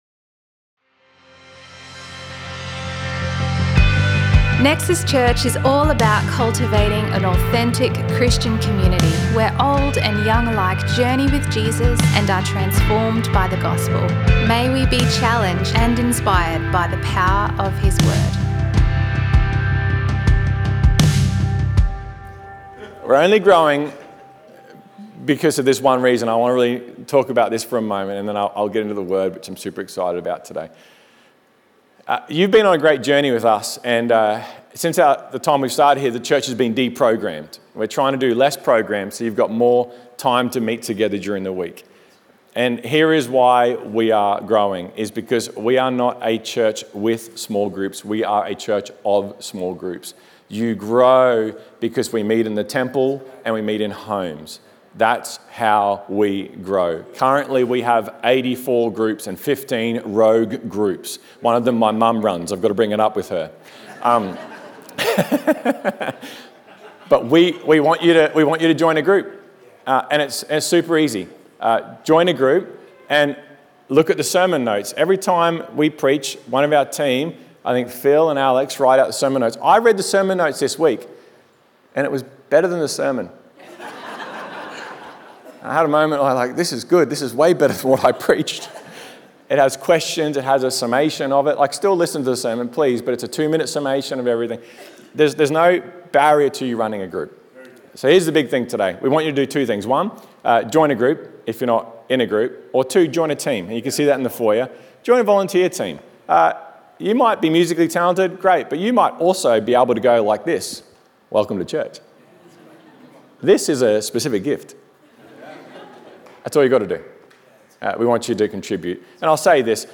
A selection of messages from Nexus Church in Brisbane, Australia.